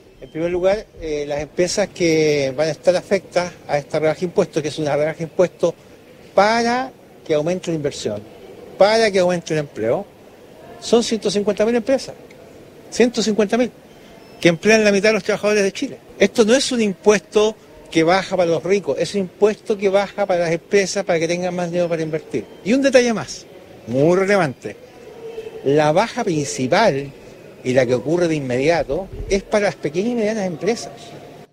Por su parte, el ministro de Hacienda, Jorge Quiroz, defendió la rebaja tributaria y aseguró que beneficiará a unas 150 mil empresas, las que concentran cerca de la mitad del empleo en el país.